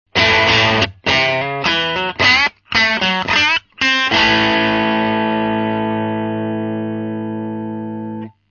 (NoEQ,NoEffectで掲載しています）
No.7 MP3 GeorgeL'sのパッチケーブルを一本使用。
Hexaと比べると派手な音ですが、何よりも中域の太さ感がギターには良いですね。
ギターからエフェクタまでの接続にCanareを使っているので、Canareのキャラクターがだいぶ前面に出てきたように聞こえます。